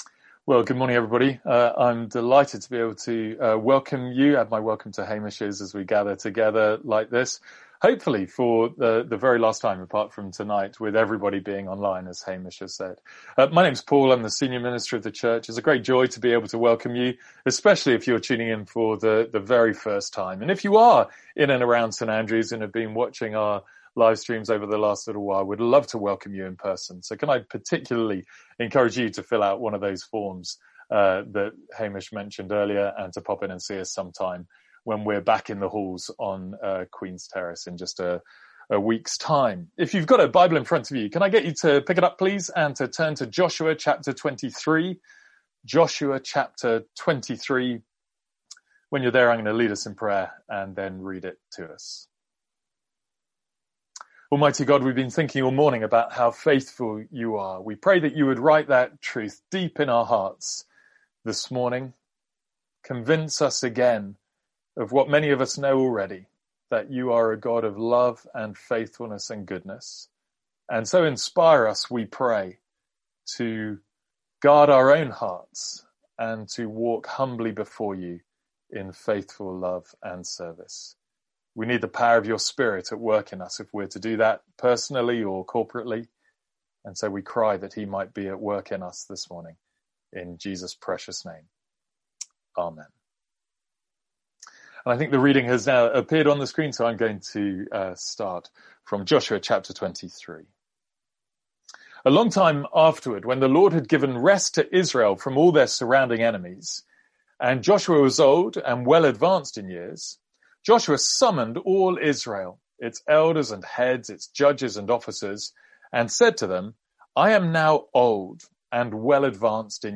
Sermons | St Andrews Free Church
From our morning series in Joshua.